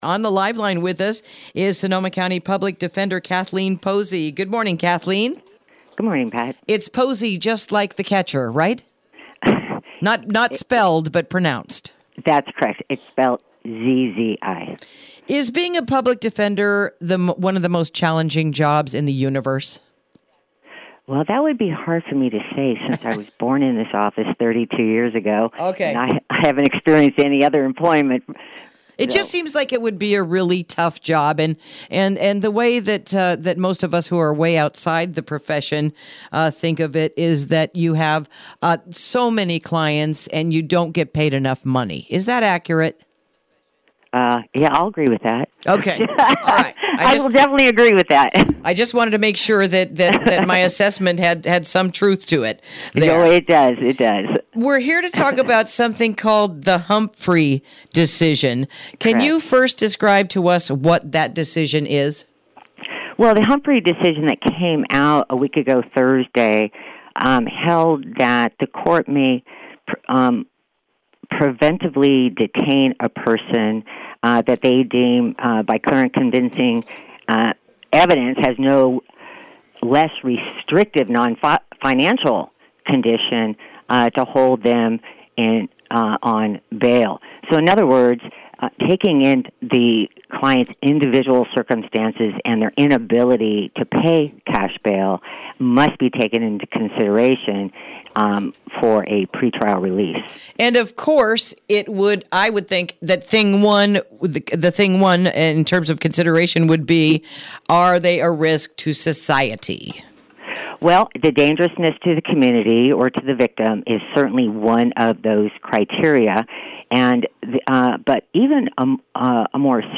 Interview: Everything You Need to Know Regarding the Humphrey Decision